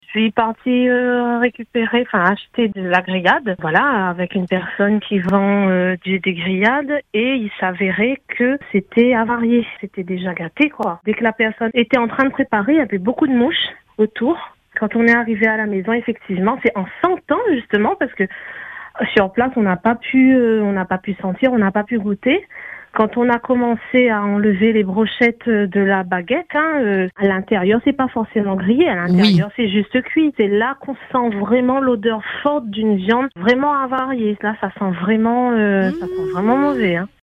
Choquée et écœurée, la cliente témoigne de sa mésaventure.